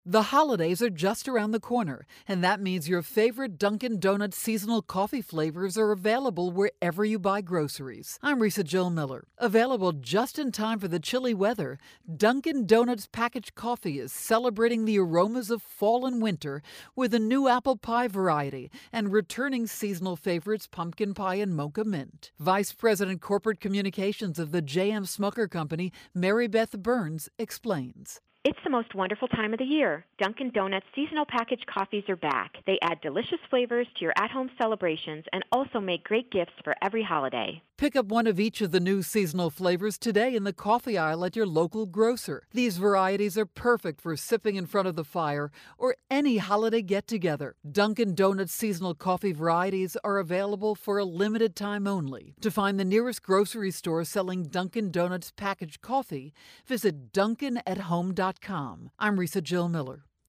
October 11, 2013Posted in: Audio News Release